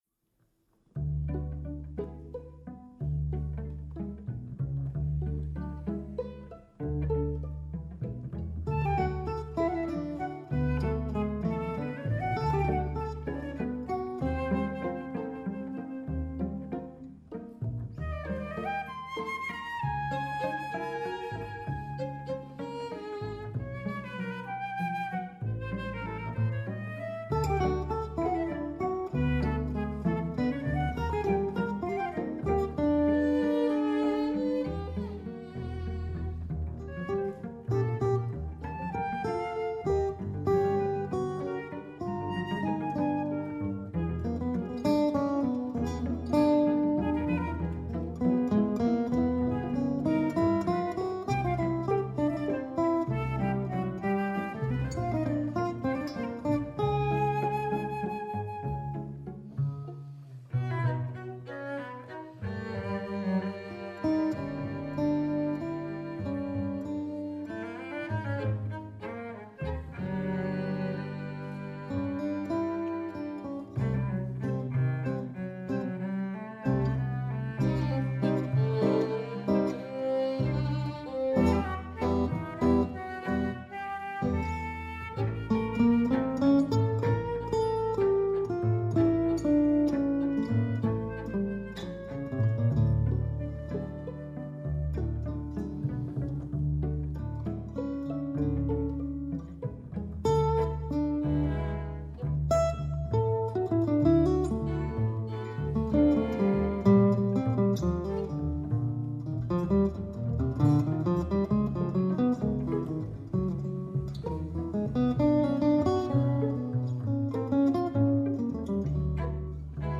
chitarra
flauto
violino
violoncello
contrabbasso